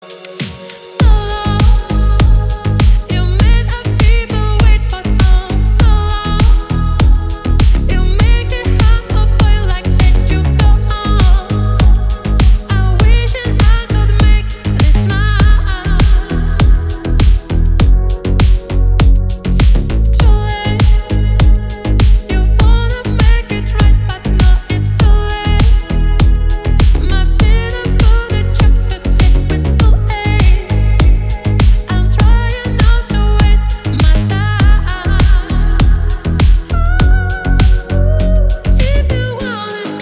• Качество: 320, Stereo
мужской вокал
deep house
мелодичные
расслабляющие